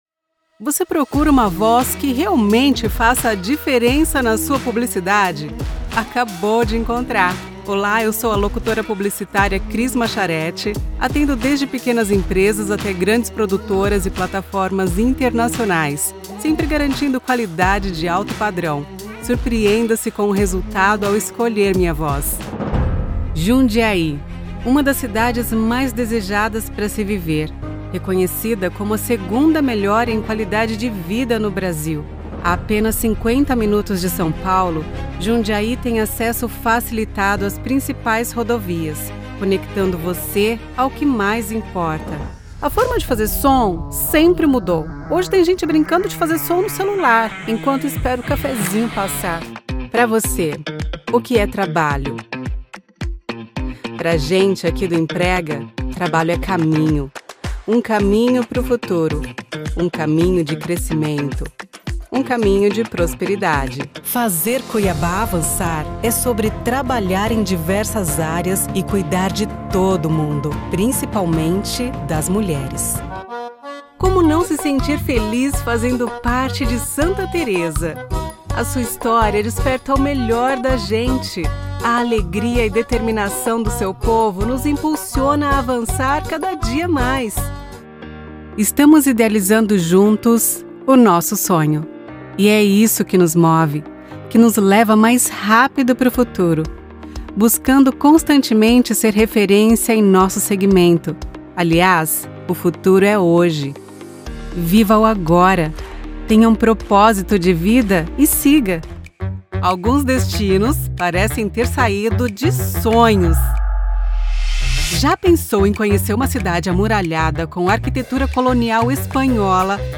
Browse professional voiceover demos.
Whether it's soft and sultry, loud and boisterous, or relatable and conversational, I've got you covered. I've got a lower register female voice but I'm a soprano singer, so my range is expansive.